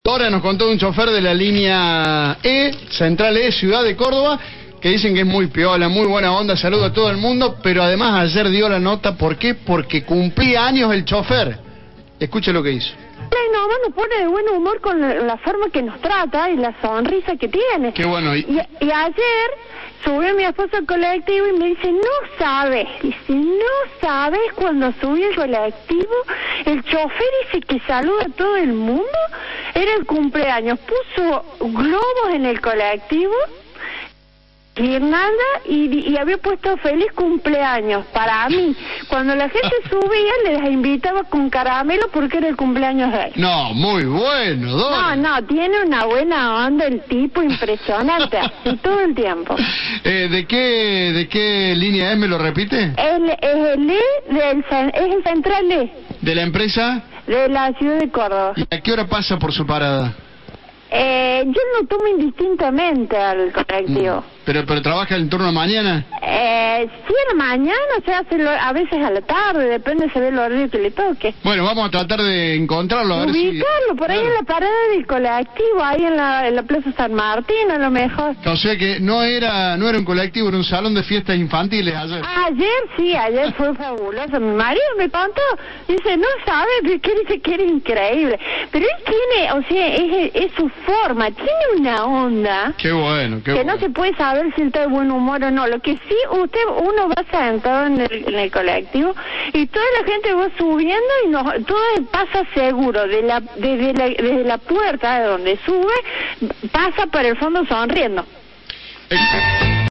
El conductor celebró su cumpleaños con los pasajeros